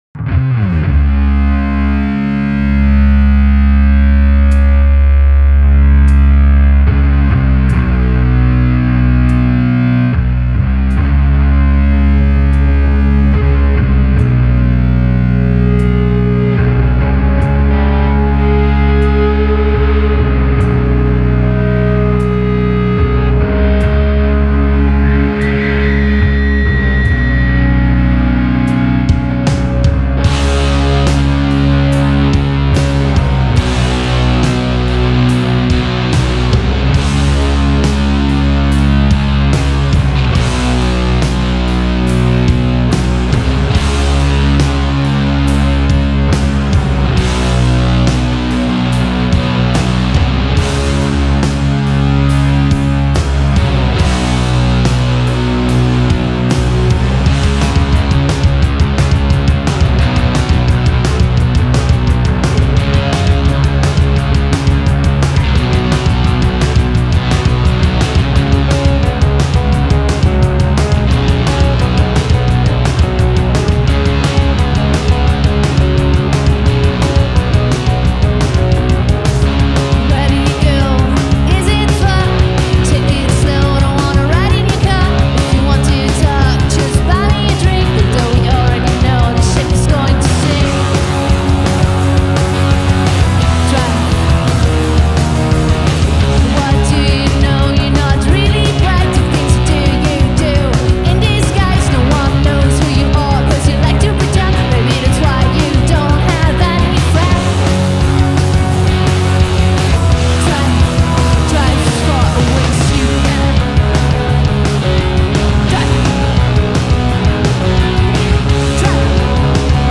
in concert from Lucerne, 2020
Further evidence the Swiss can rock.
heavier psychedelic wash